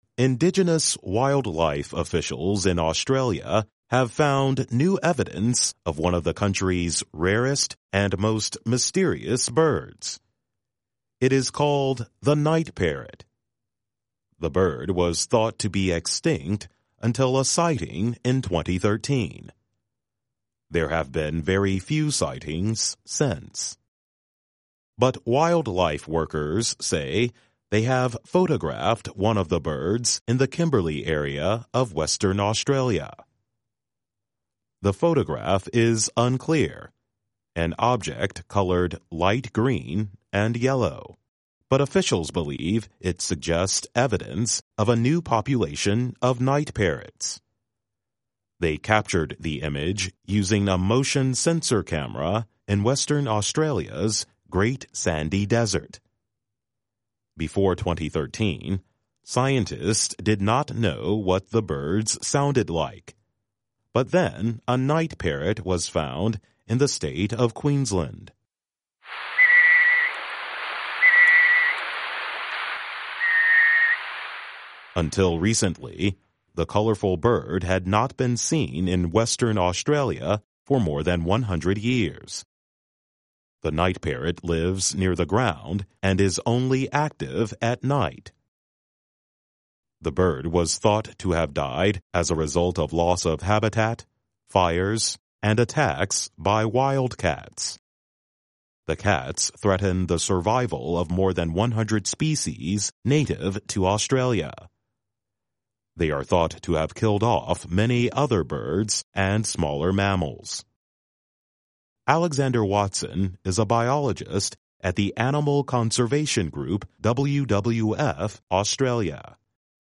News
慢速英语:野生动物官员称在澳洲发现夜鹦鹉